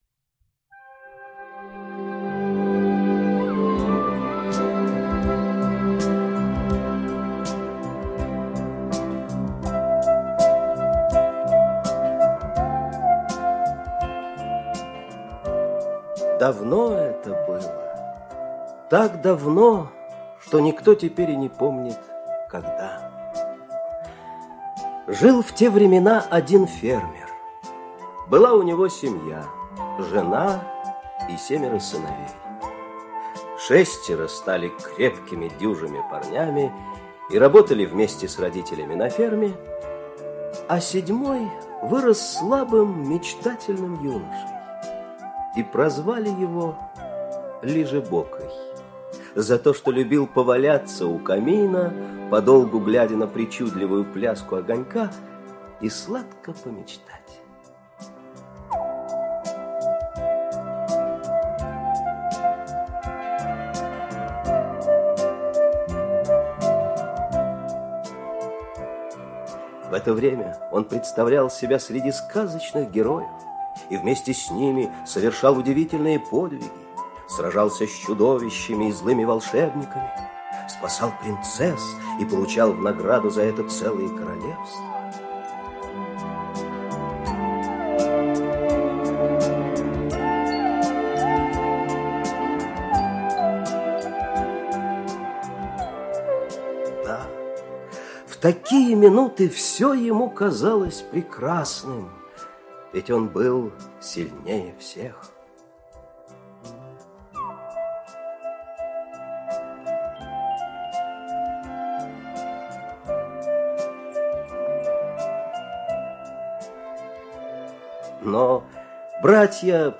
Владыка штормов - шотландская аудиосказка - слушать онлайн